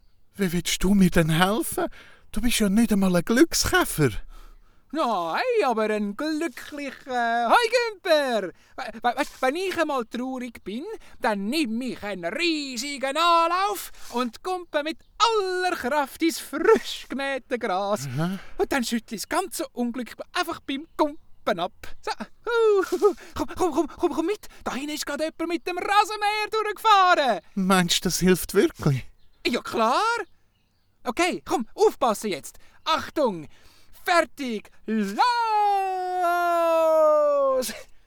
★ Abentüür uf de Wiese Folge 3 ★ Dialekt Hörspiel ★ Löffelspitzer